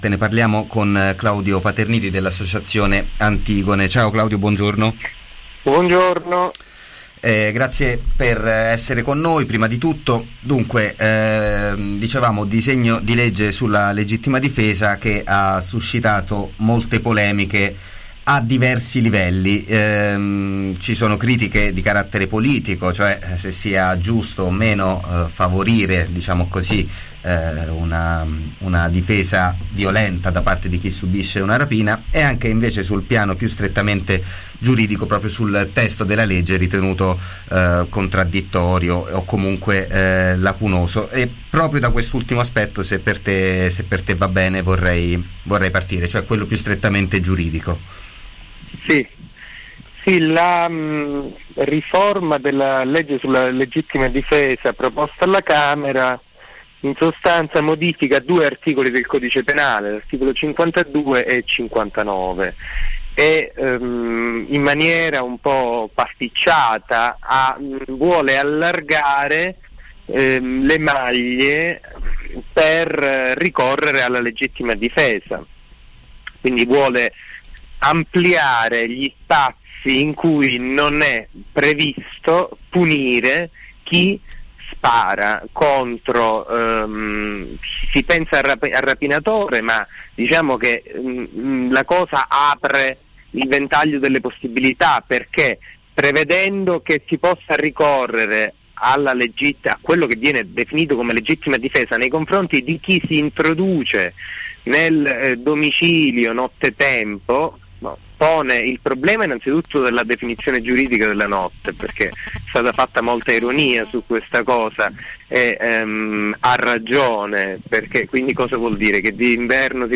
Ddl legittima difesa: intervista